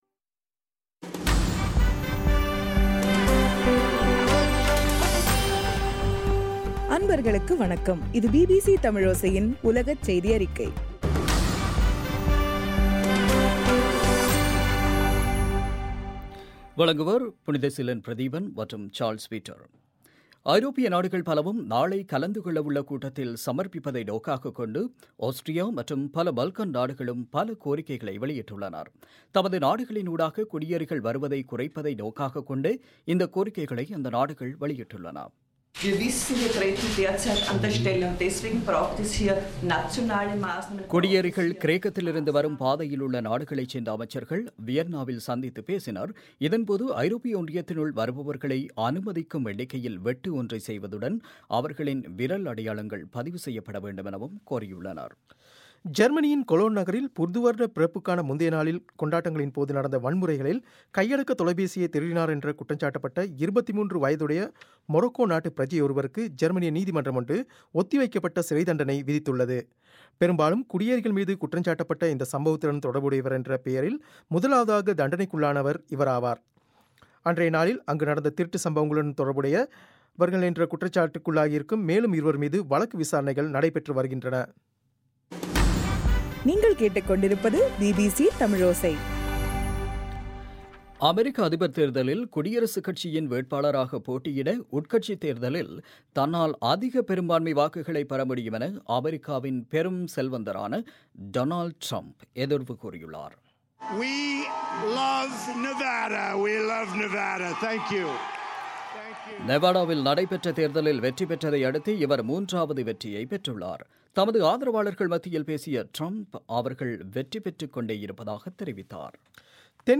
பிப்ரவரி 24 பிபிசியின் உலகச் செய்திகள்